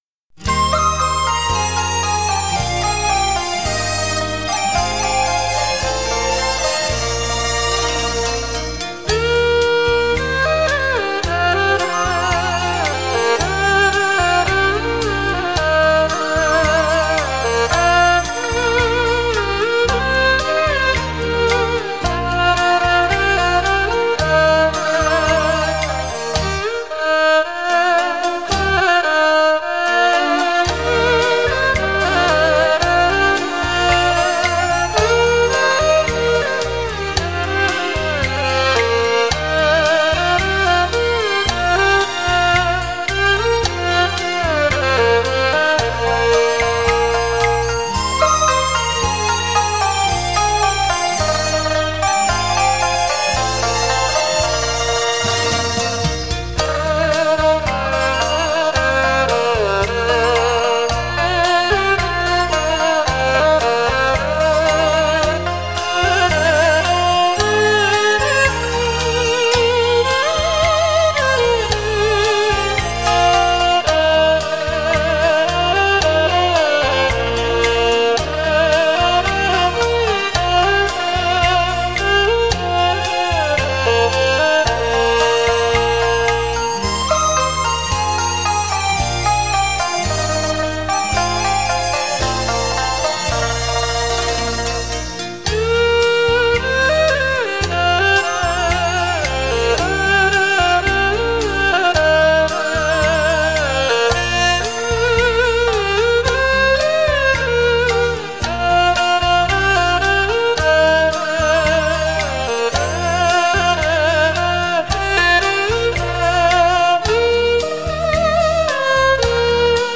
轻快流畅，生机盎然